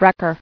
[wreck·er]